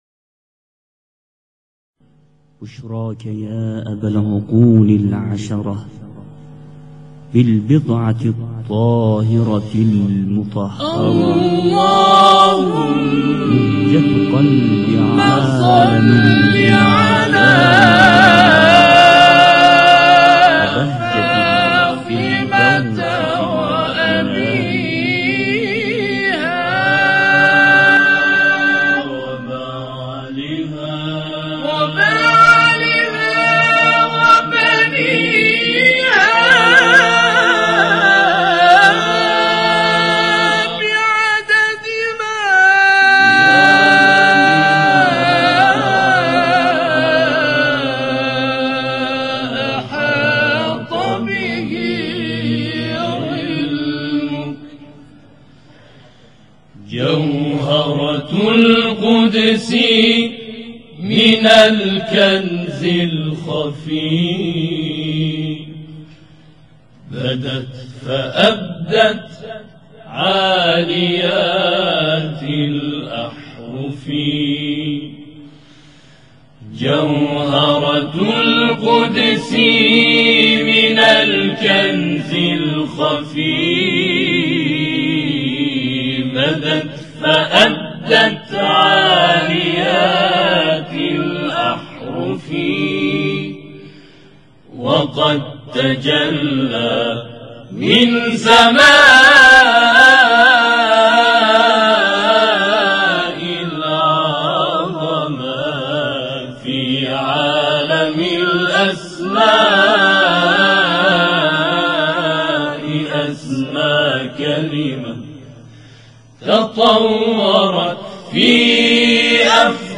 همخوانی ناب و نورانی